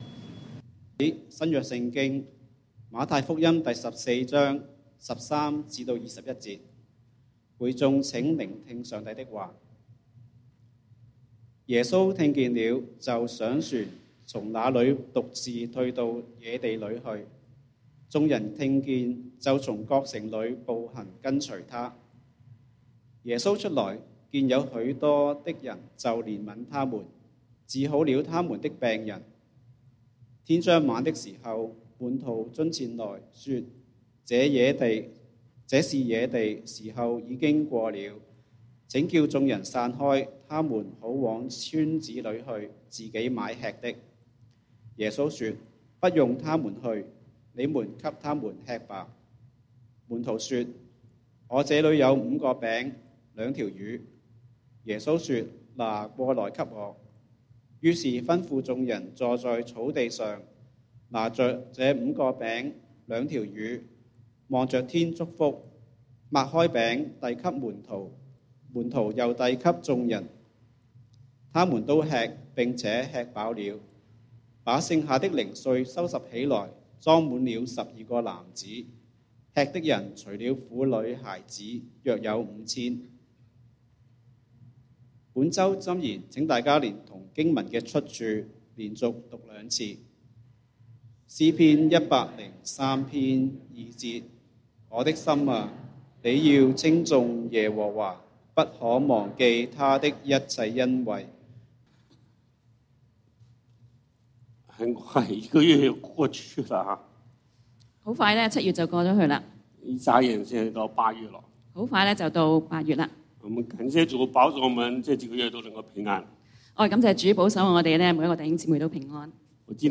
講道經文：《馬太福音》Matthew 14:13-21 本週箴言：《詩篇》Psalms 103:2 「我的心哪，你要稱頌耶和華！